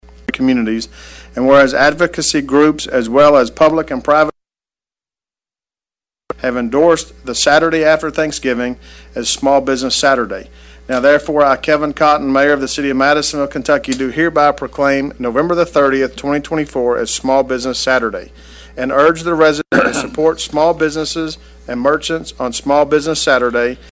During last week’s City Council meeting, Mayor Cotton issued a proclamation to honor local small businesses and their significant contributions to the local economy and community.